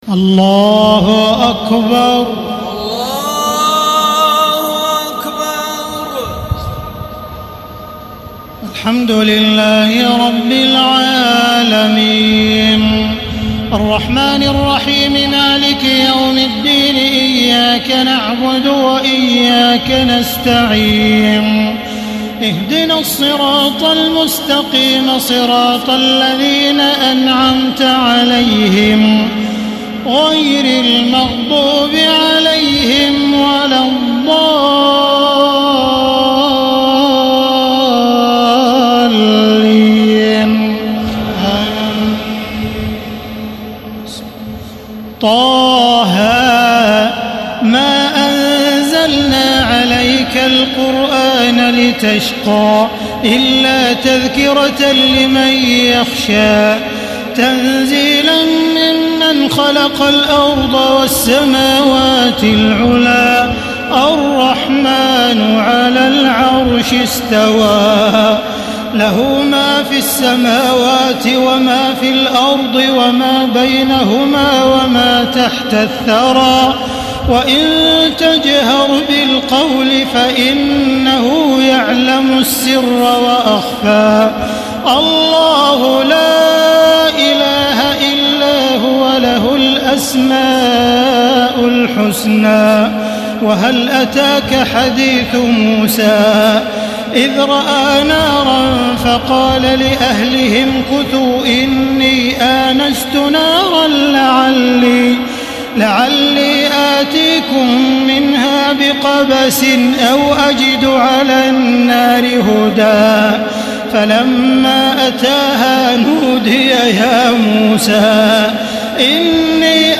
تراويح الليلة السادسة عشر رمضان 1435هـ سورة طه كاملة Taraweeh 16 st night Ramadan 1435H from Surah Taa-Haa > تراويح الحرم المكي عام 1435 🕋 > التراويح - تلاوات الحرمين